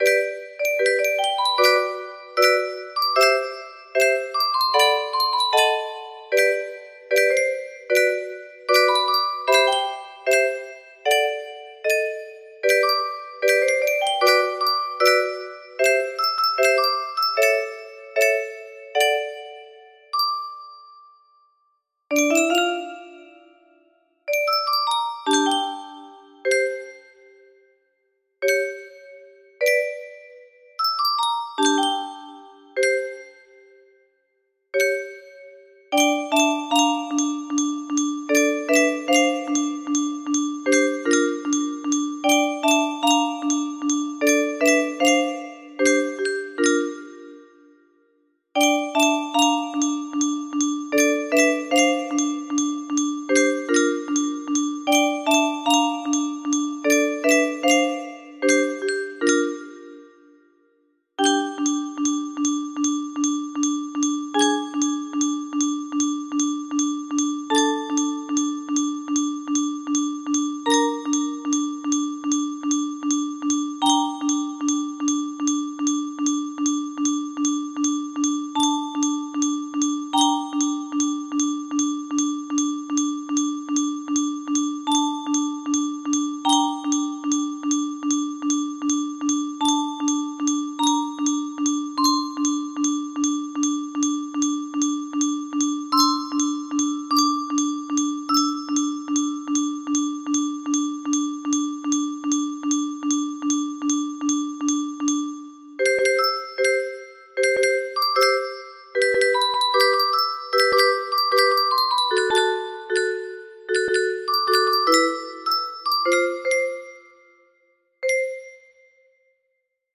adapted for 30 notes